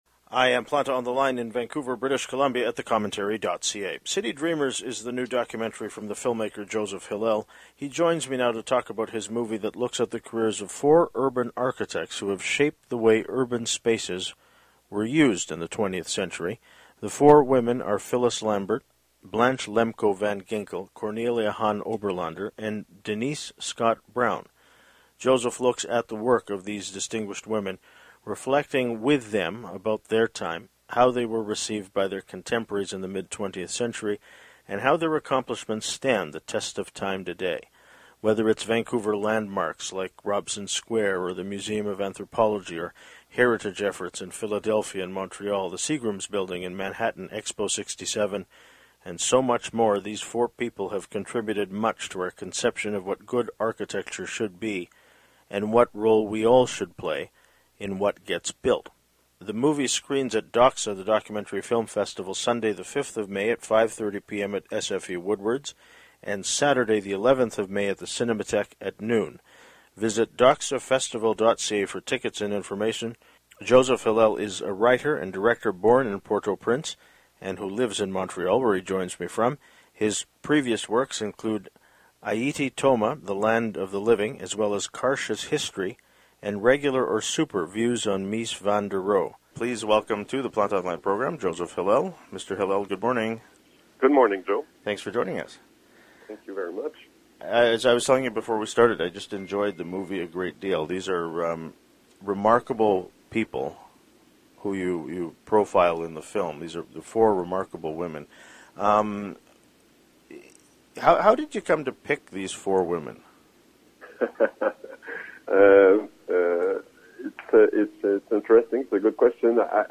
He joins me now to talk about his movie, that looks at the careers of four urban architects who have shaped the way urban spaces were used in the 20 th century.